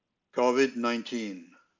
COVID-19 (alternative pronunciation)
COVID-19: /ˈkəʊ.vɪdˌnaɪnˈtiːn/ [COvid-nineTEEN]
COVID-19: /ˈkoʊ.vɪdˌnaɪnˈtiːn/ [COvid-nineTEEN]